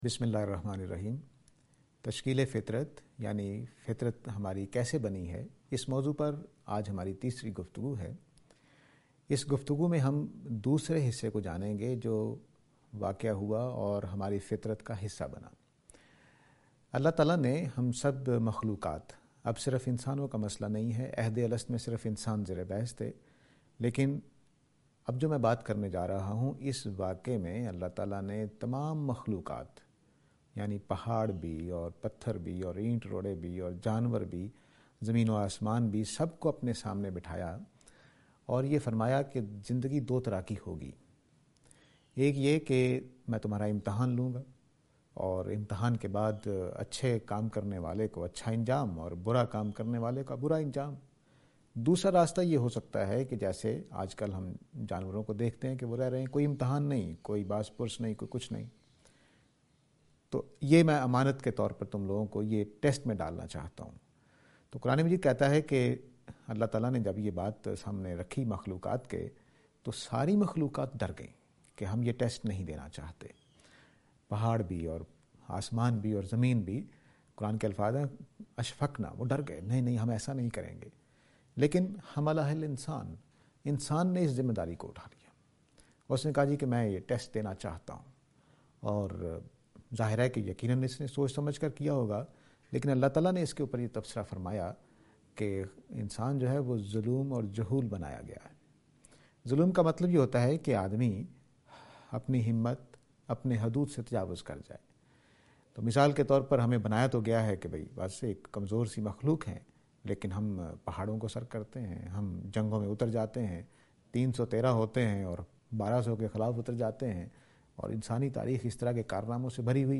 This lecture is and attempt to answer the question "Construction of Nature (Commitment)".